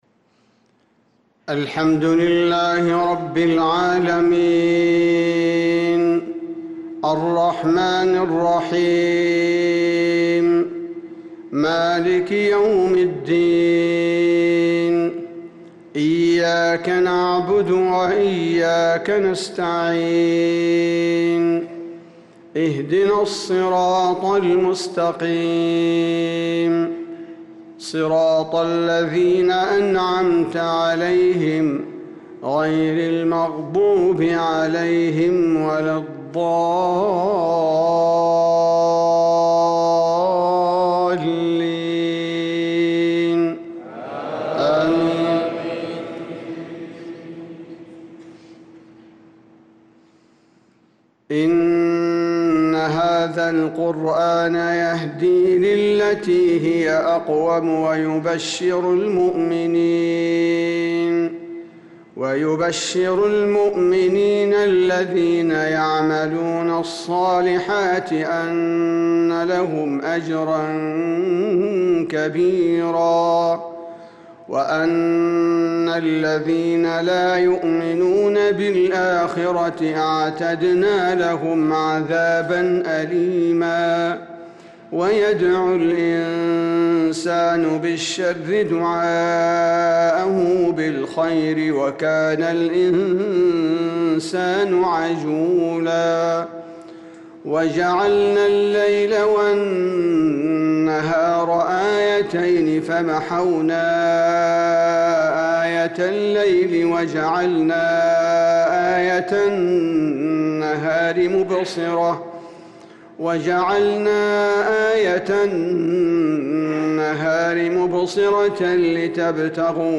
صلاة الفجر للقارئ عبدالباري الثبيتي 16 ذو القعدة 1445 هـ